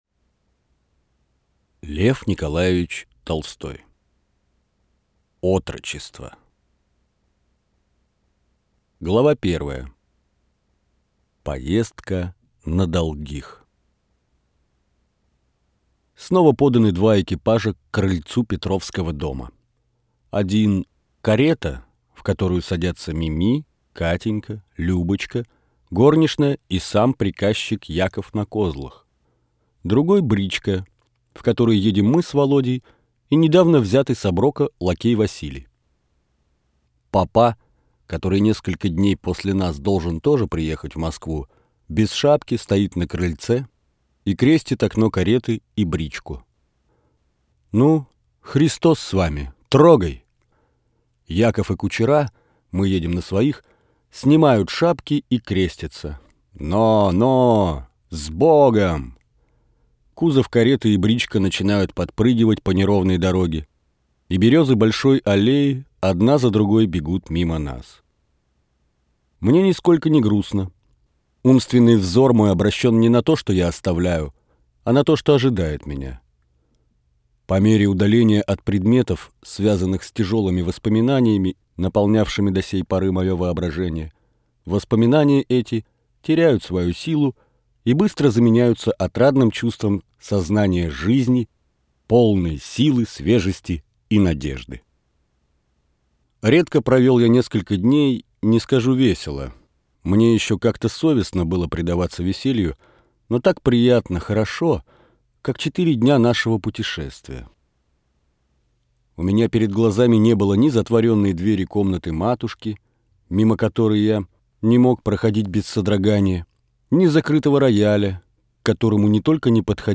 Аудиокнига Отрочество - купить, скачать и слушать онлайн | КнигоПоиск